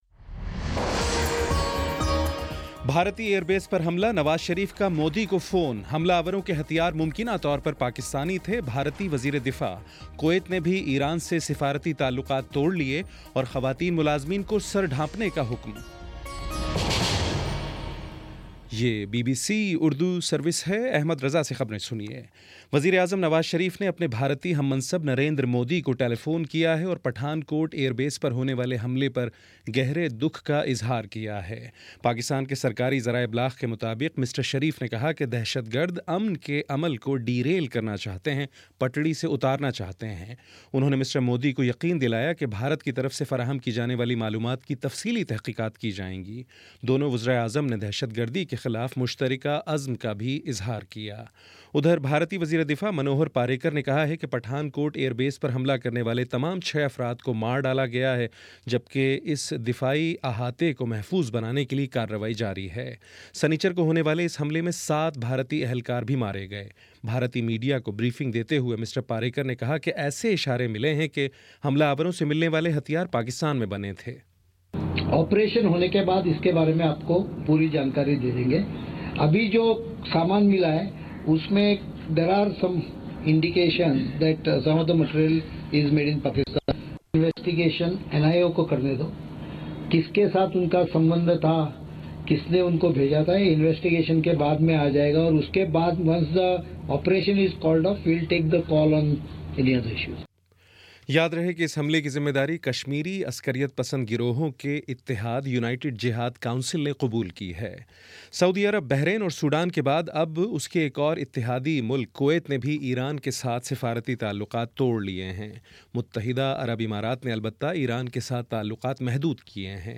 جنوری 05 : شام پانچ بجے کا نیوز بُلیٹن